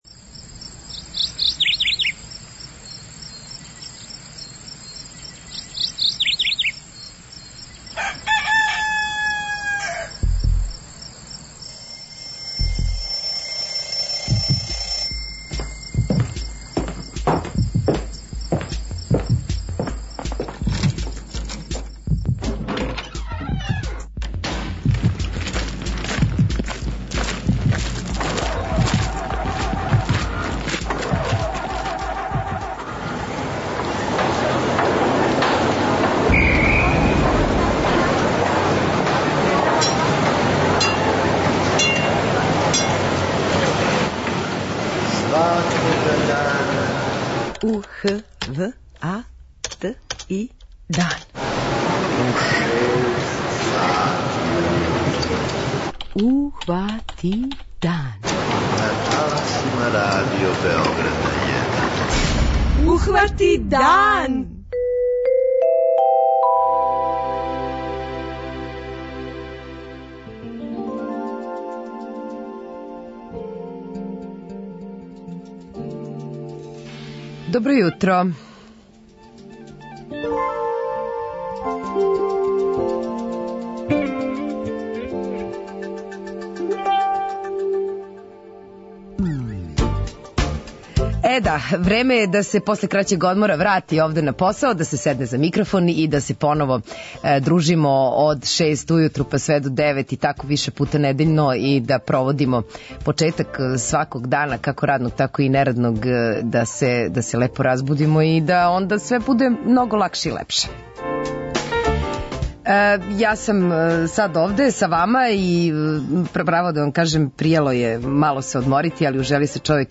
Гости јутарњег програма су чланови ове екипе.
преузми : 43.15 MB Ухвати дан Autor: Група аутора Јутарњи програм Радио Београда 1!